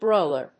音節bróil・er 発音記号・読み方
/ˈbrɔɪlɝ(米国英語), ˈbrɔɪlɜ:(英国英語)/